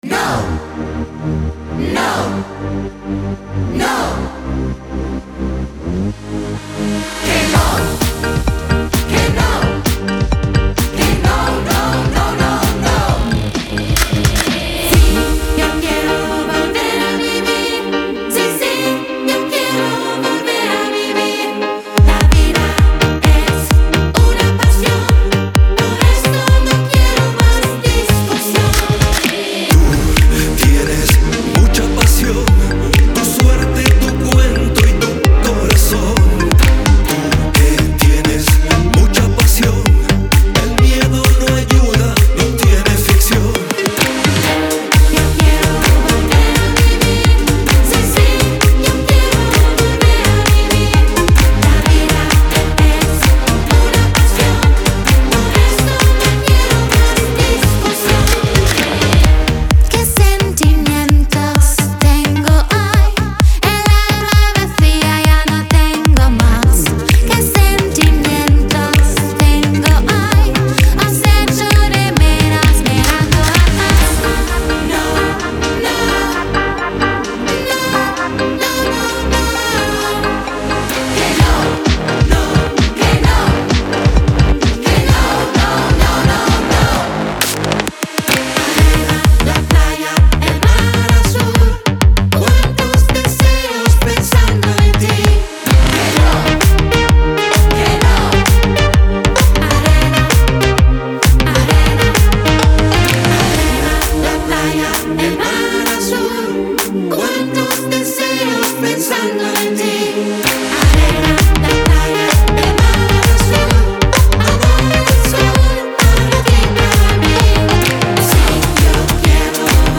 spanischen Dance-Pop-Song